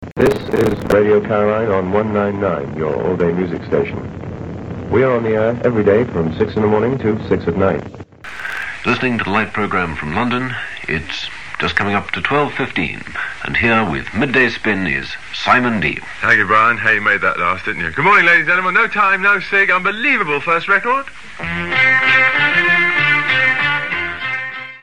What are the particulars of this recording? Enjoy the beautiful culture clash on this clip, just after the continuity.